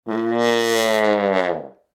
На этой странице собраны звуки грустного тромбона (sad trombone) — узнаваемые меланхоличные мотивы, часто используемые в кино и юмористических роликах.
Грустный тромбон – Краткий вариант